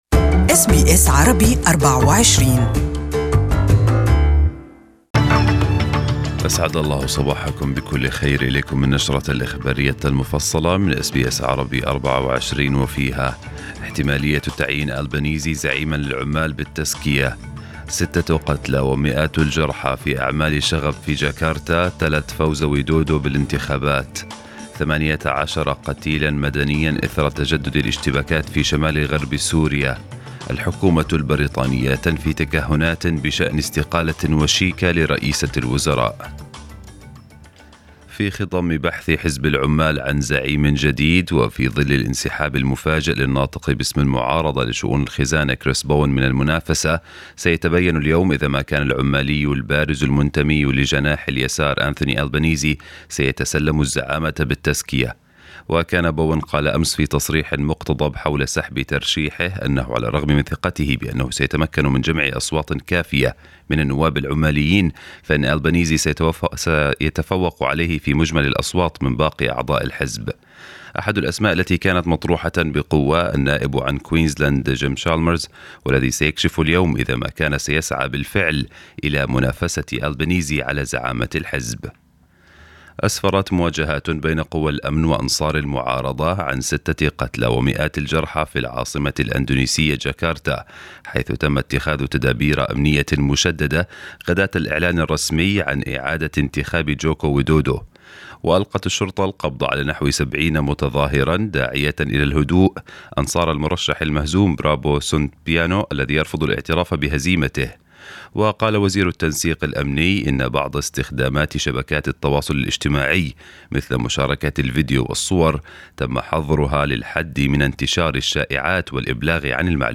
News bulletin in Arabic 23/5/2019
News bulletin for the morning